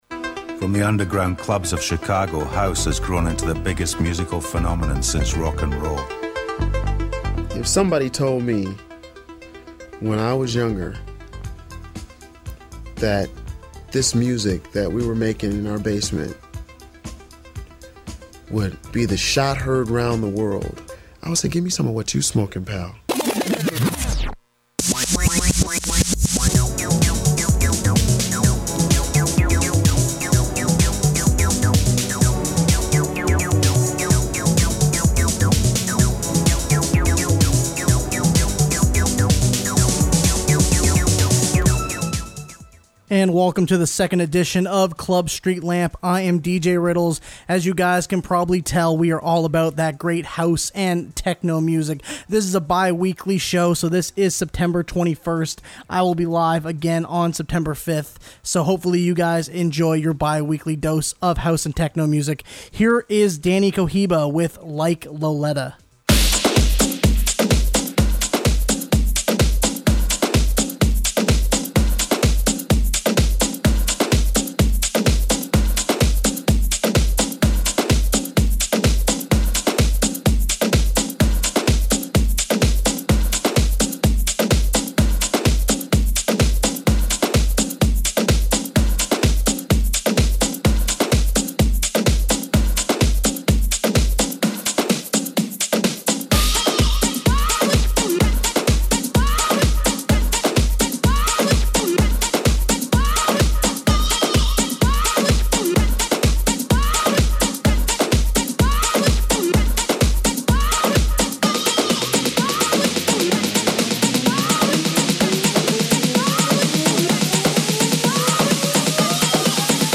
Late Night House/Techno Music Program